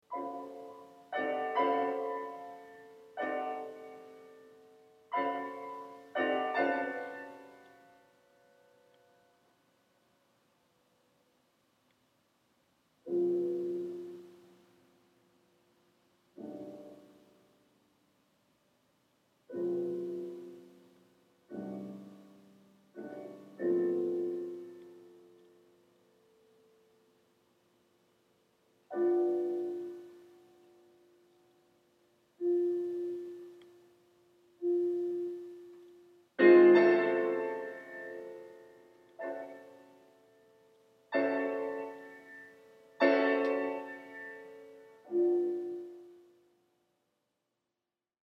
Serene and meditative